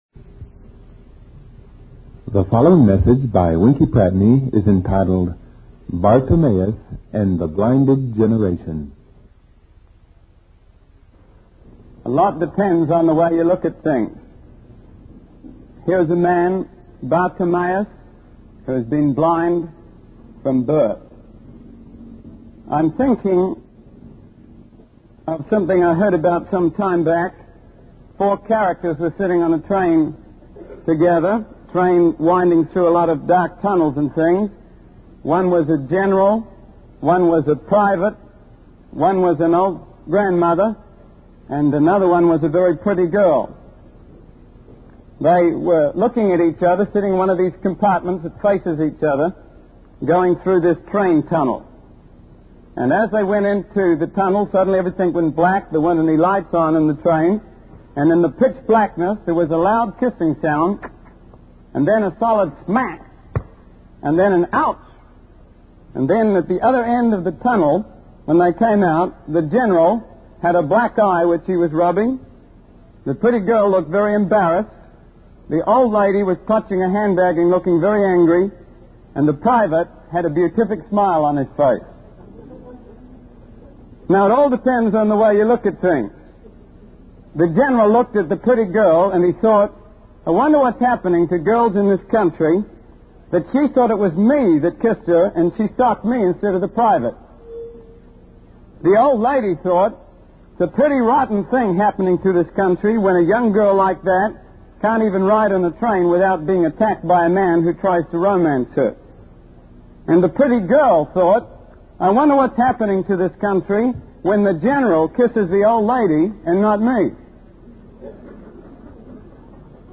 In this sermon, the speaker discusses the impact of television on children and their ability to adapt quickly to change. He explains that television has taught children to shift between different scenes and emotions without feeling overwhelmed.